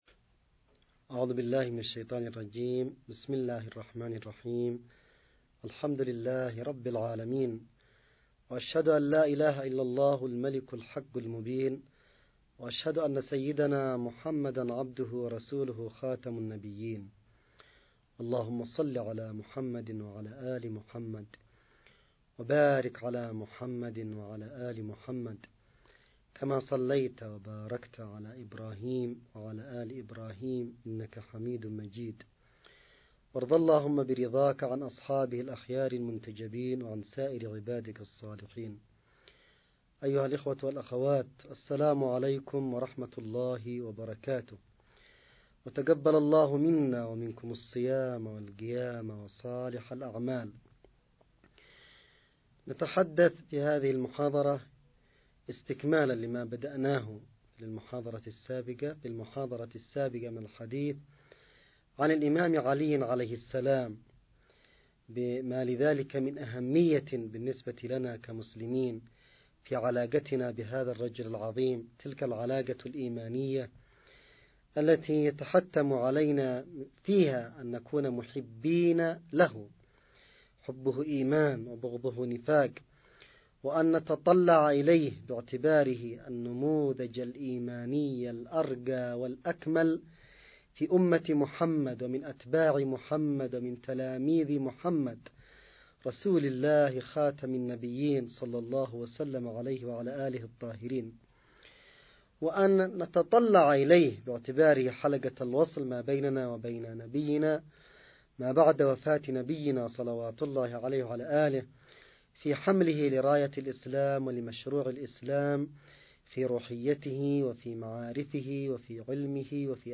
نص+أستماع للمحاضرة الرمضانية الـ13 للسيد الحوثي – ذكرى استشهاد الإمام علي عليه السلام 02
المحاضرة_الرمضانية_الـ13_للسيد_عبدالملك.mp3